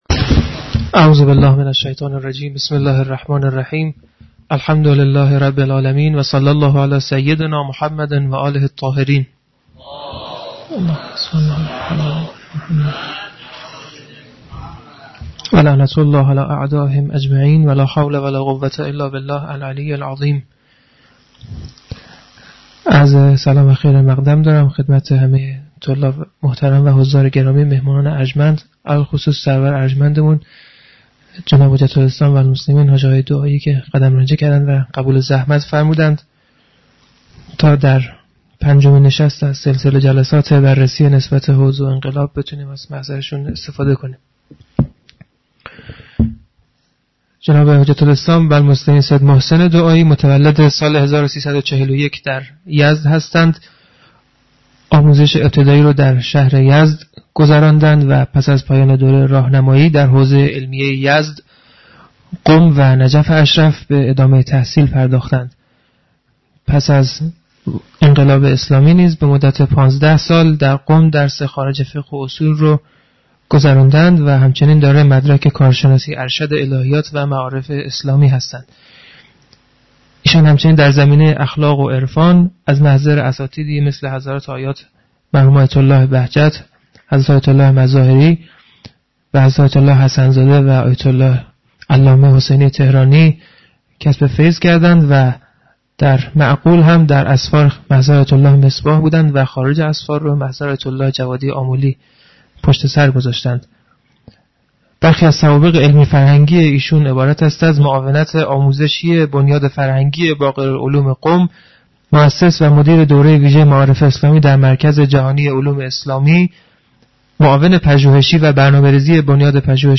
• پنجمین نشست همایش تبیین نسبت حوزه و انقلاب